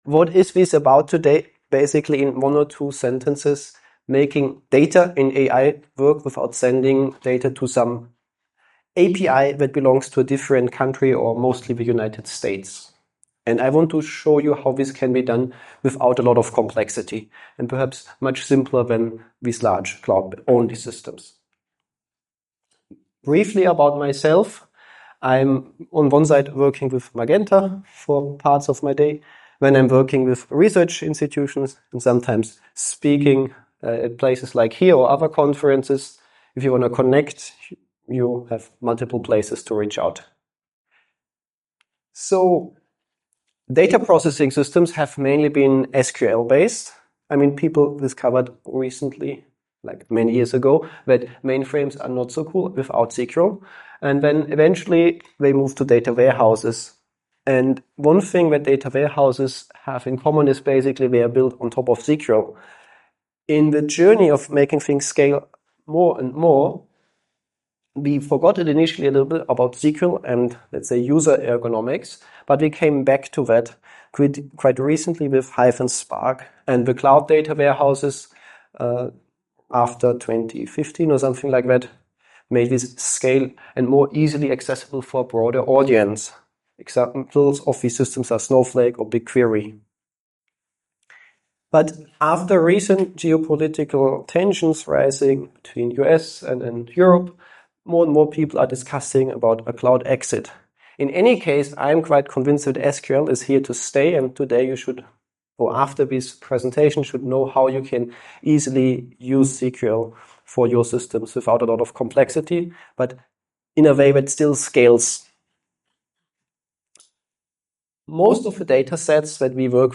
Join us for a practical talk on building a fast, portable, and sovereign data & AI stack with: